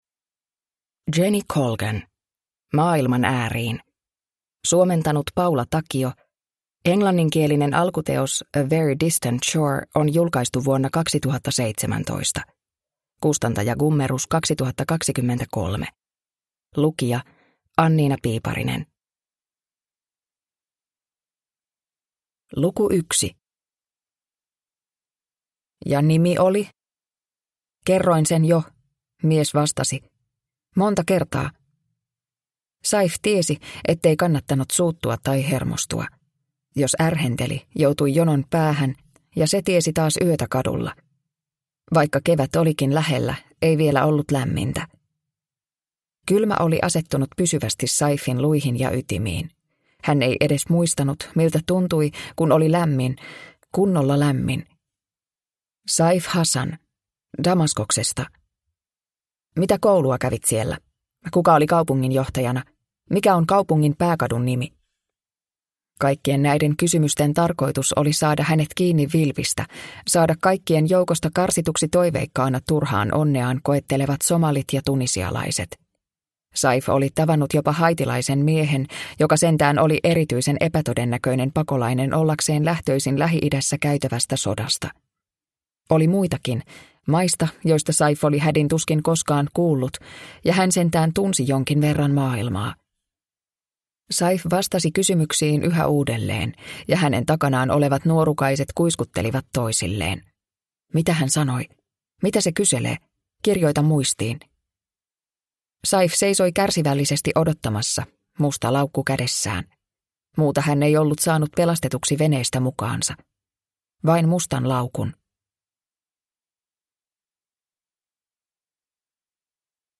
Maailman ääriin – Ljudbok – Laddas ner